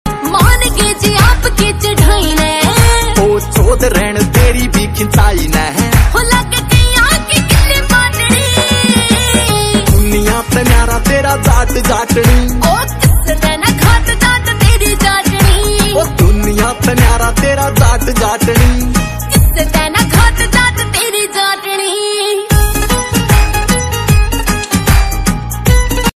New Haryanvi Song 2023